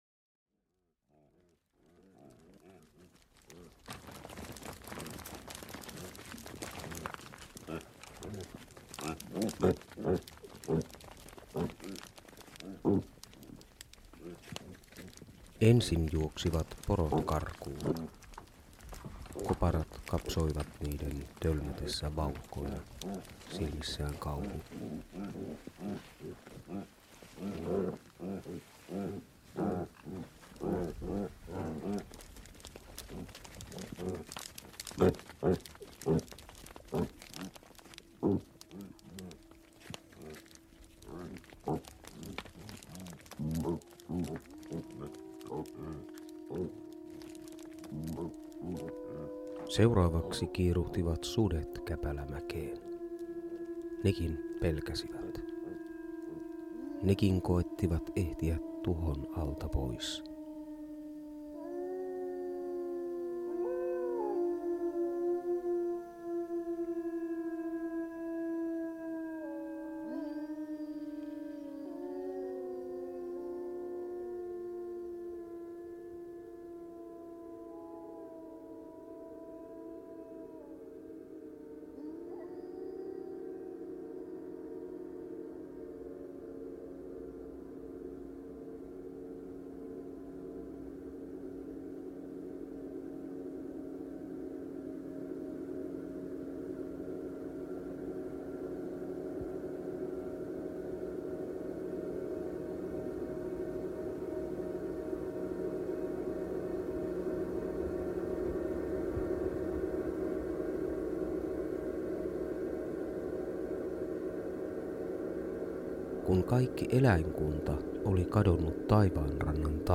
Pieni äänitarina jääkauden synnystä.